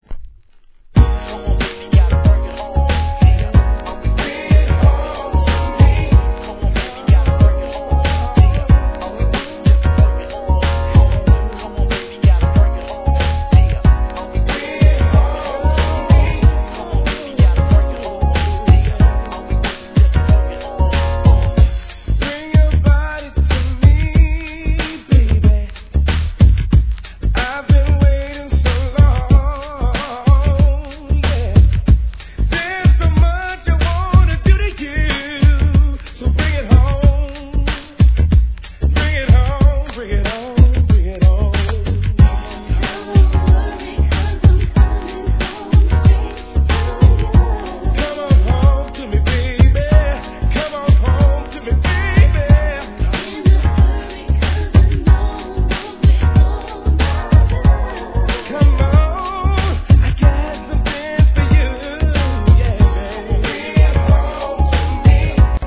HIP HOP/R&B
マイナー盤ながら、その本格派なヴォーカルは'90s R&Bファン要クリック!!